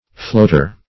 Floater \Float"er\, n.